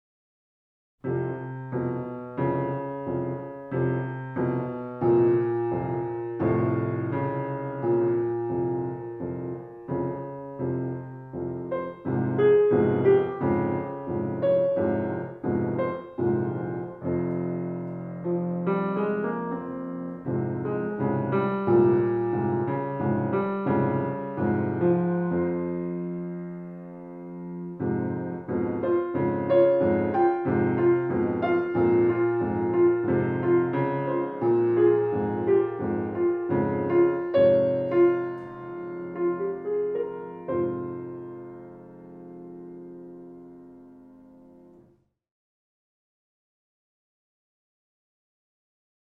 Musik zum Mantra 13 M — cantabile